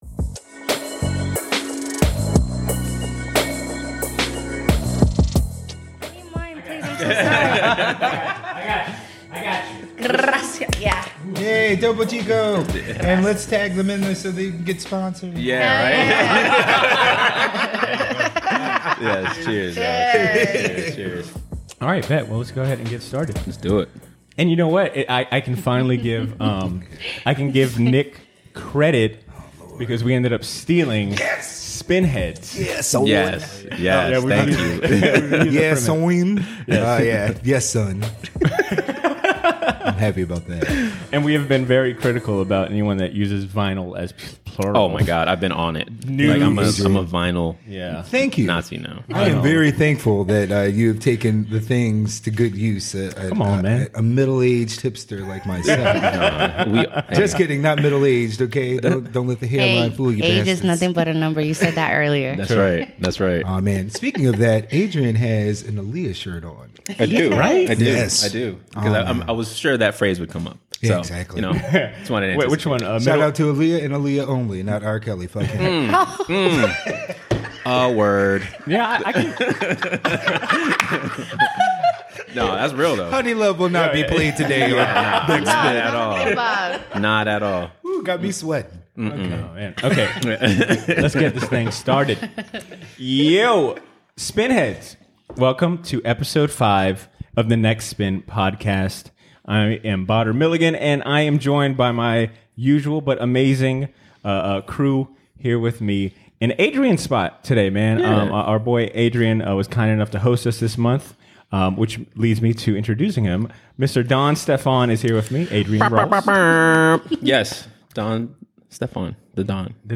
The collective talk about the challenges of shopping for international music, discuss the influence of the diaspora on different genres of music, and share what they learned from researching their ancestries.